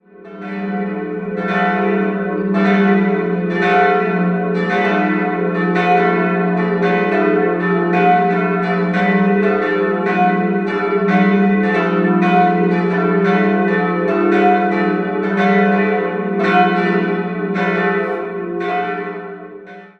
3-stimmiges Geläut: e'-fis'-a' (verzogen) Die große Glocke wurde 1745 von Paul Dietrich, die kleine 1752 von Johann Florido, jeweils in Straubing, gegossen. Die mittlere entstand 1606 bei Fiering in Regensburg.